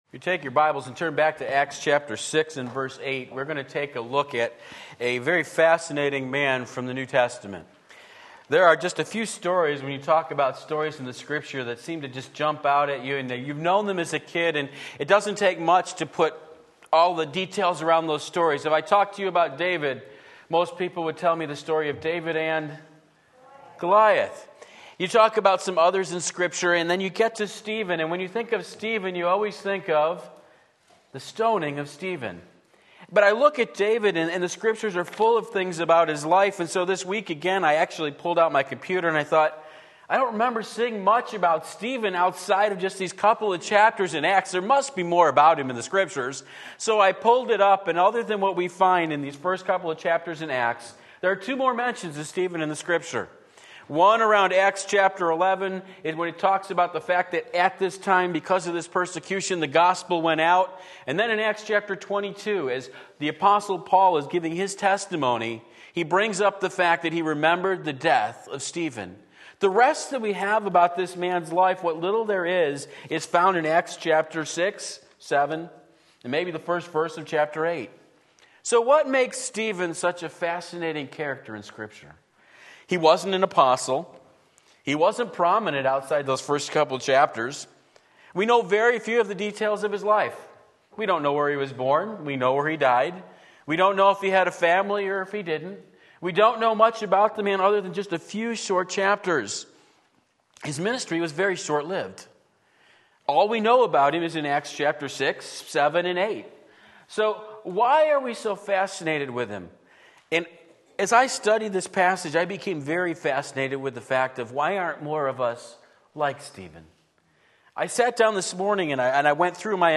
Sermon Link
7:54-60 Sunday Morning Service